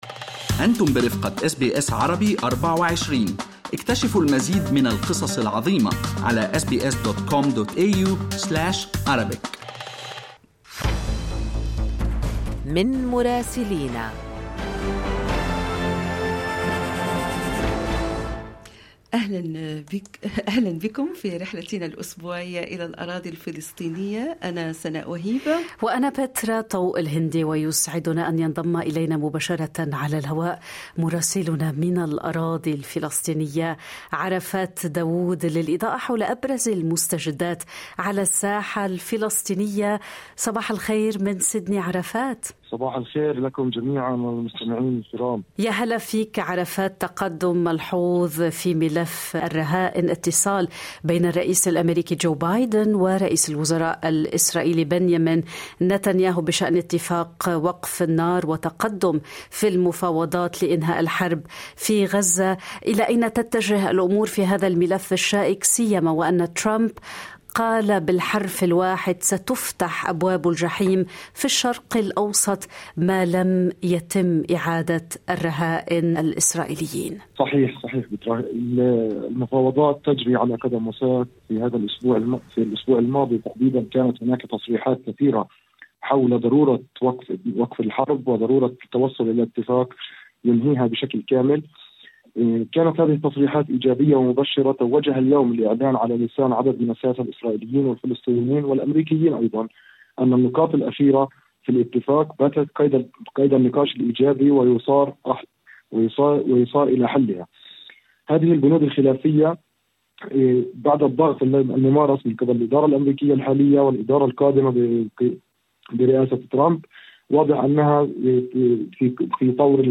يمكنكم الاستماع إلى التقرير الصوتي من رام الله بالضغط على التسجيل الصوتي أعلاه.